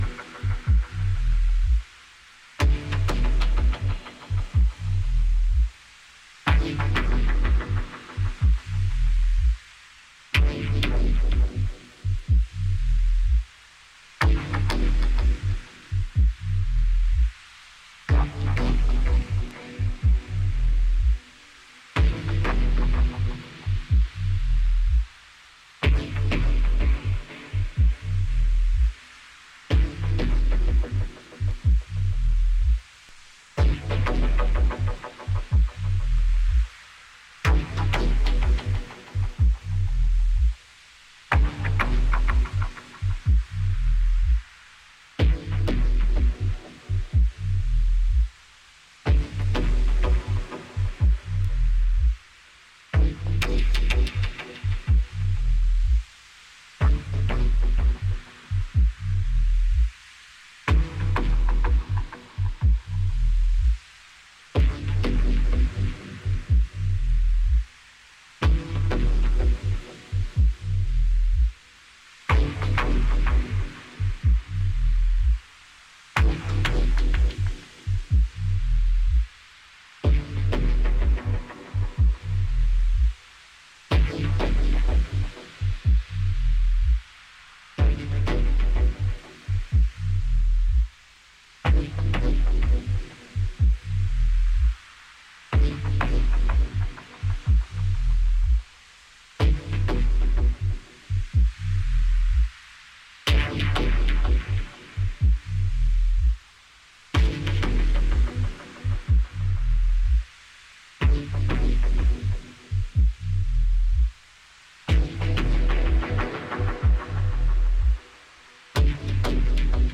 ハーフテンポで更なる深みへと引き込んでいくB面では、輪をかけた驚異的なドープネスを展開。